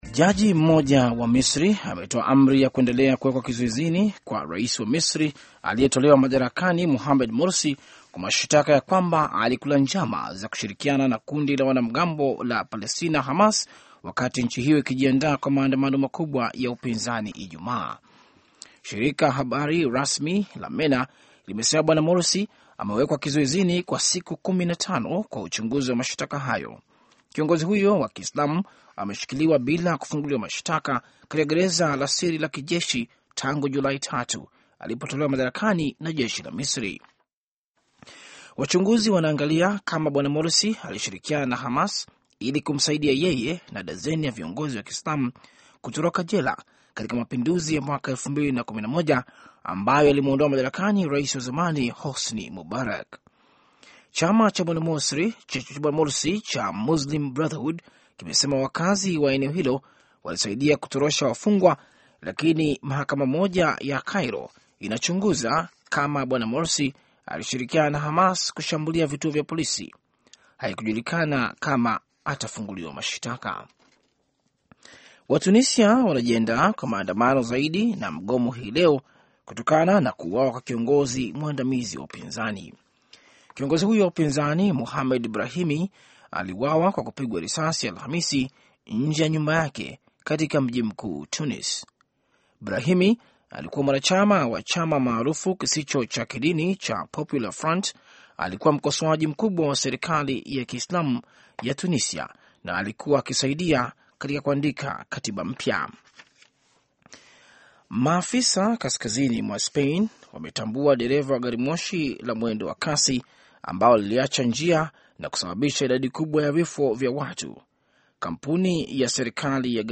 Taarifa ya Habari